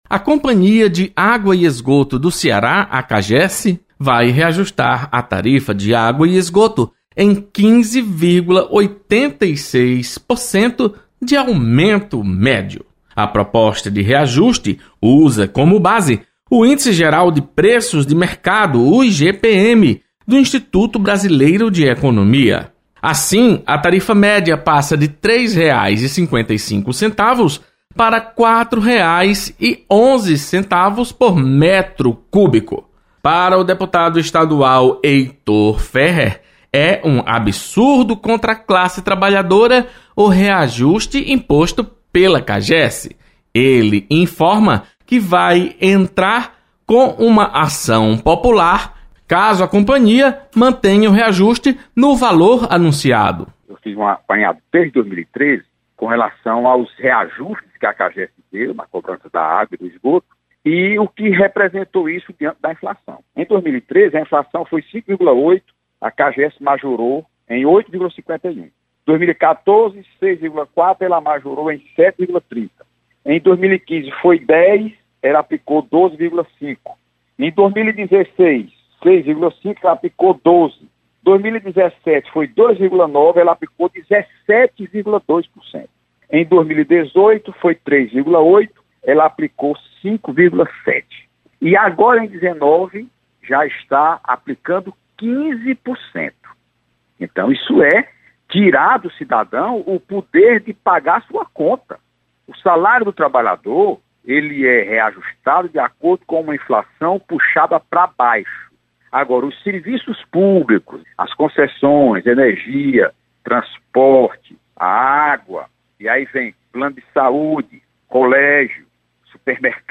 Deputado Heitor Férrer anuncia ação para barrar reajuste da tarifa de água e esgoto. Repórter